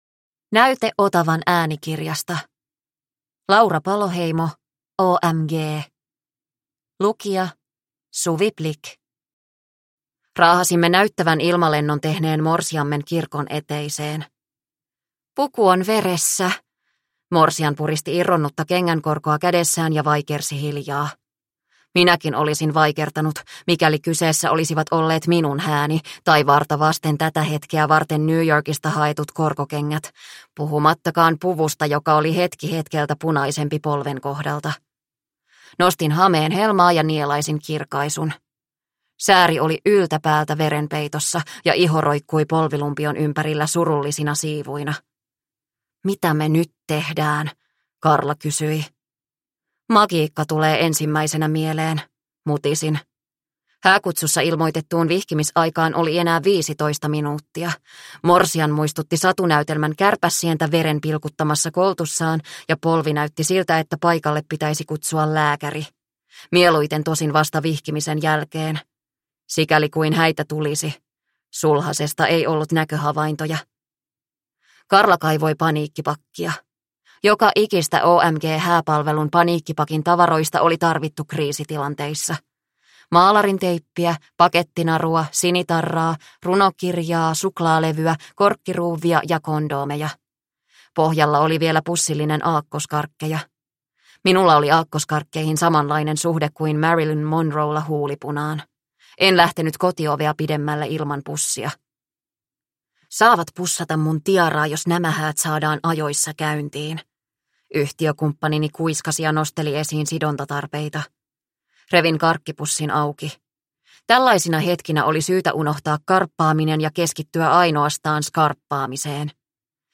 OMG – Ljudbok – Laddas ner